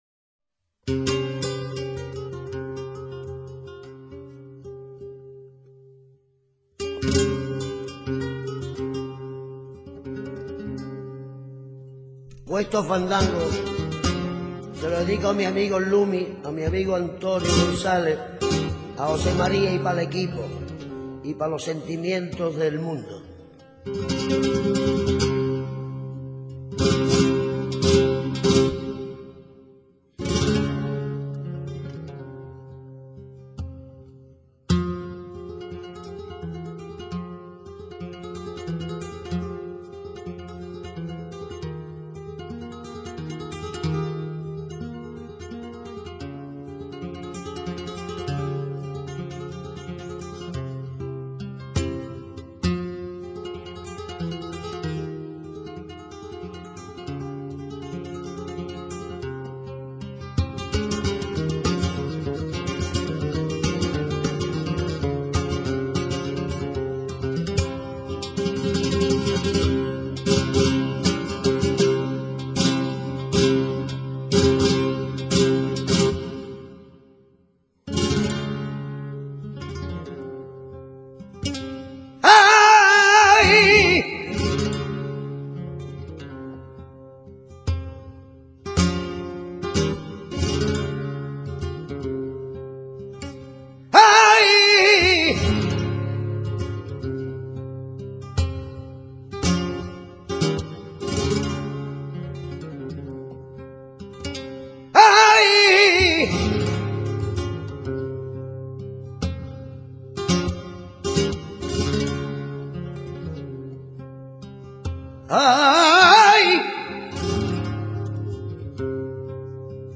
FANDANGO. m. [Del port. fado, y �ste del lat. fatus, hado.] Cante con copla de cuatro o cinco versos octos�labos, que en ocasiones se convierten en seis por repetici�n de uno de ellos, y cuyo nombre tambi�n corresponde al baile que acompa�a. Aunque en el principio fue cante para bailar, en la actualidad muchas de sus variantes son cantes para escuchar, tanto de naturaleza comarcal como los de creaci�n personal o art�sticos.
fandango.mp3